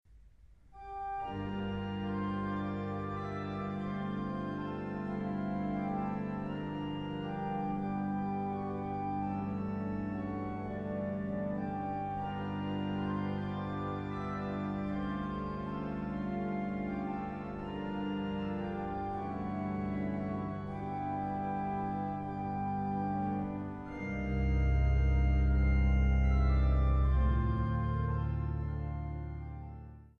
Recorded at St Matthew's Church, Northampton.